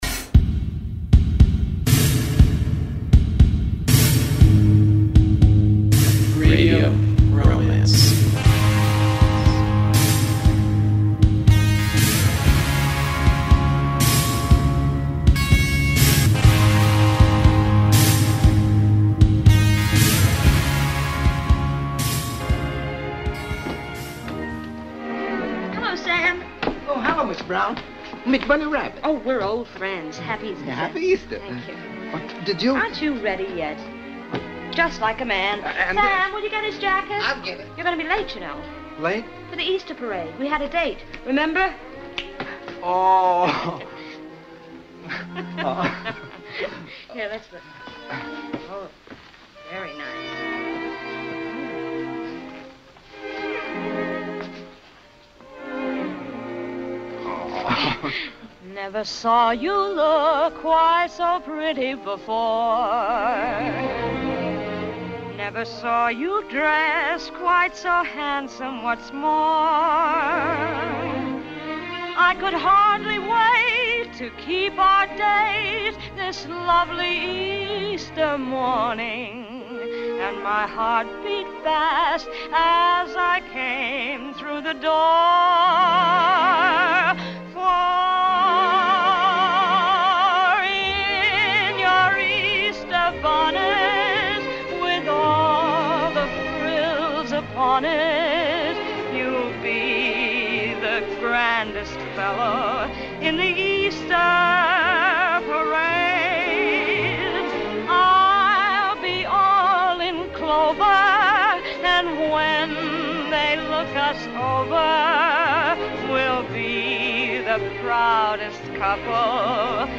Canzoni d'amore, di desiderio, di malinconia, di emozioni, di batticuore.